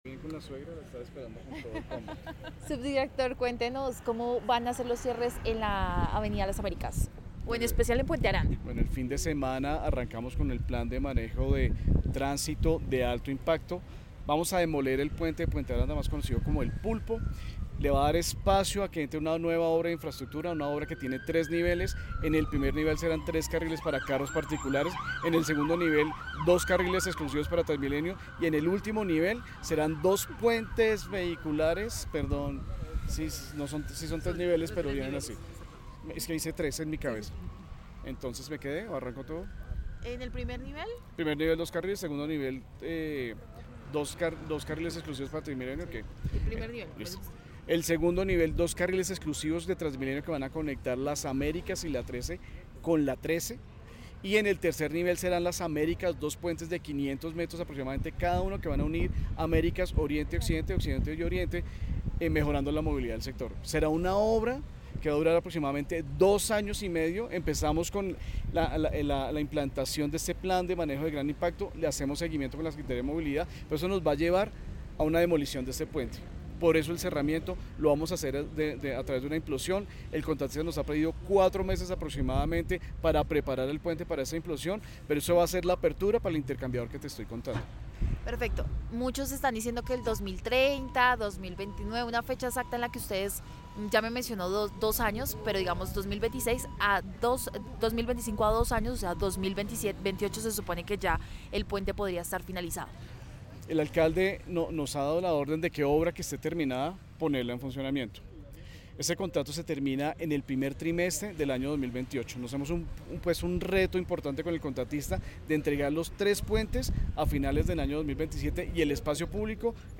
Caracol Radio habló con el subdirector del Instituto de Desarrollo Urbano (IDU), Nelson Mauricio Reina, quien explicó los detalles de las obras en la calle 13, que inician este fin de semana con un plan de tránsito de alto impacto.